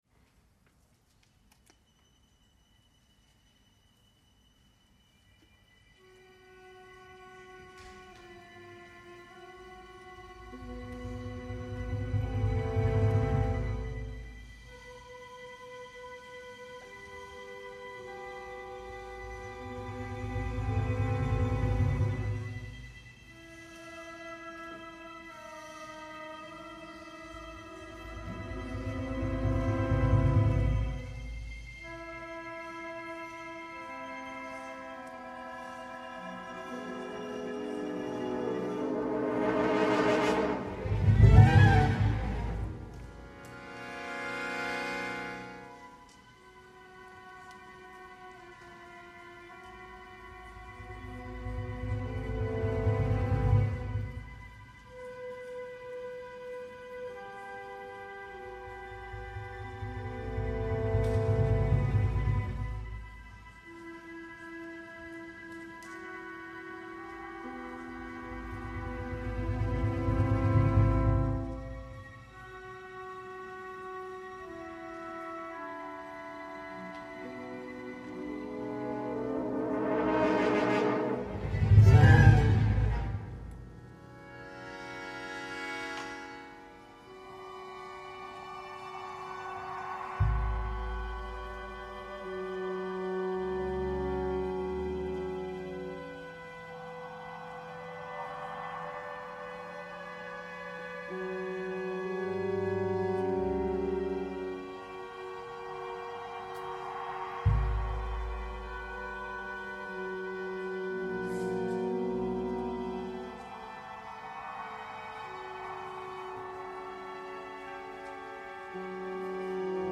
Incontro con Silvia Colasanti prima compositrice a cui il Teatro alla Scala commissiona un’opera